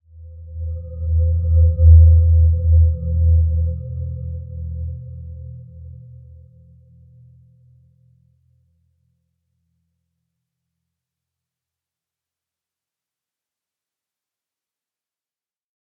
Dreamy-Fifths-E2-p.wav